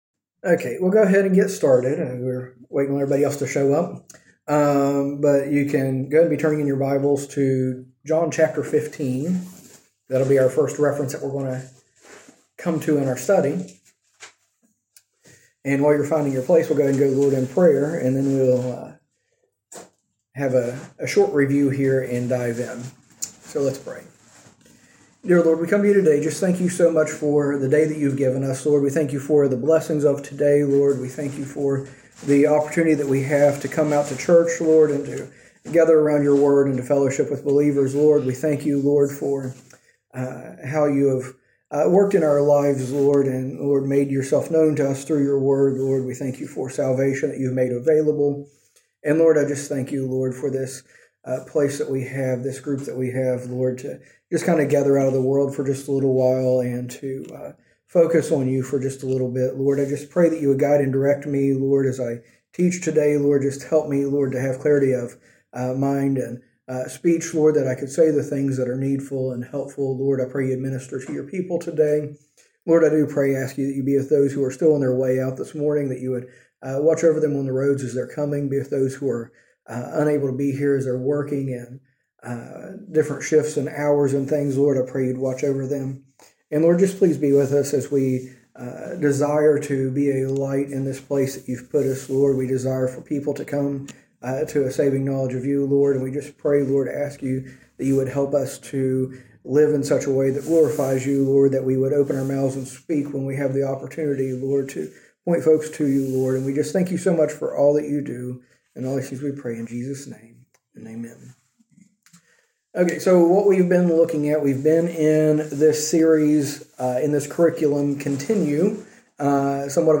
A message from the series "Continue."